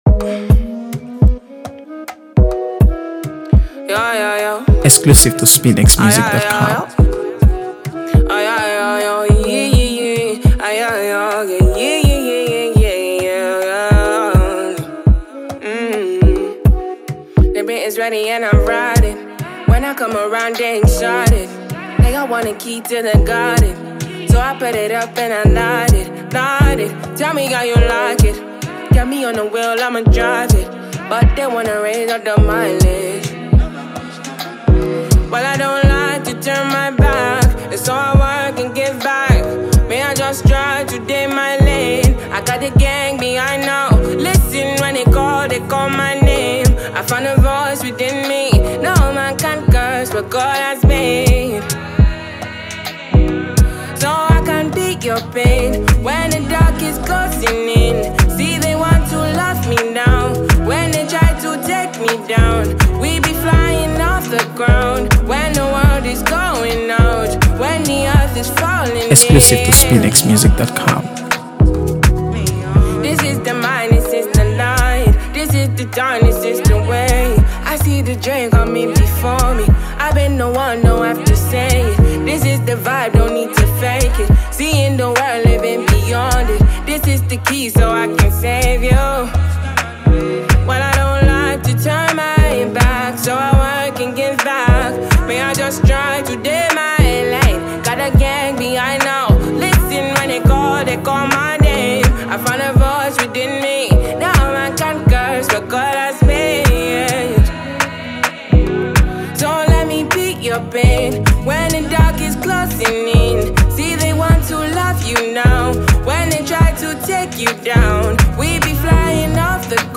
Nigerian alternative singer
catchy single